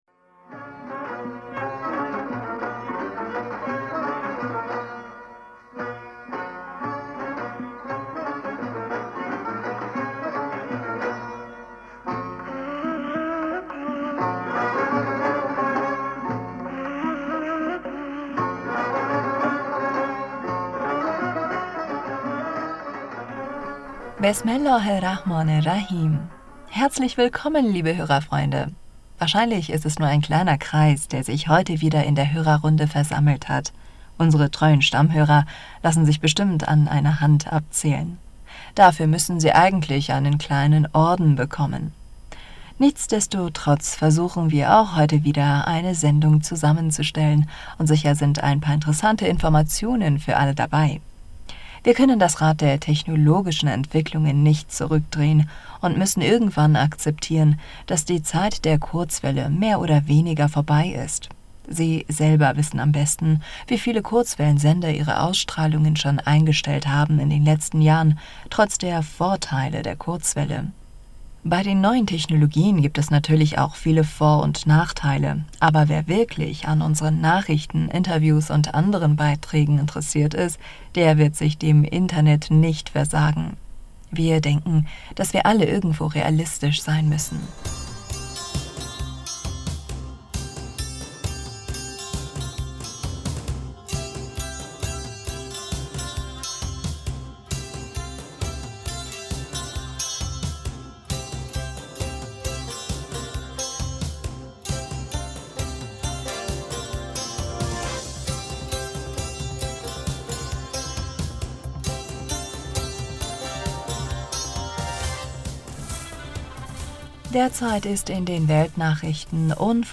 Hörerpostsendung am 13.